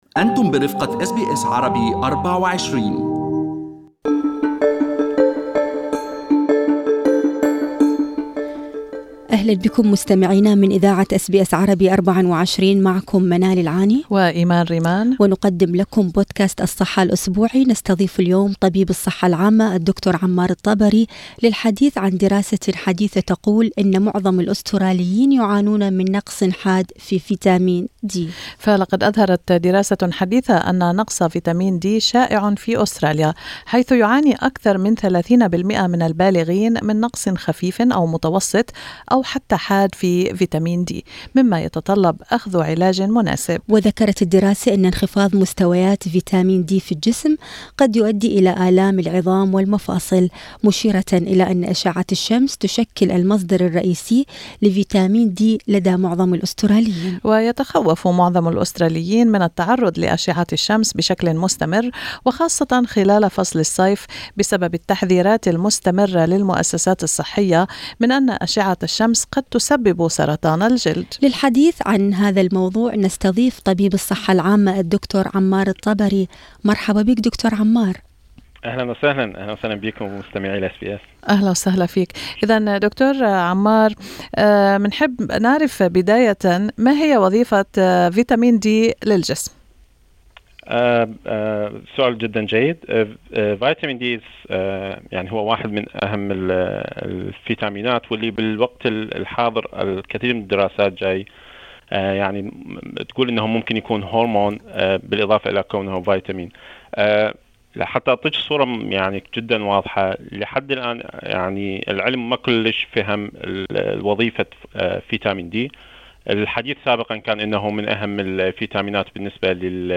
المزيد في التدوين الصوتي اعلاه مع طبيب الصحة العامة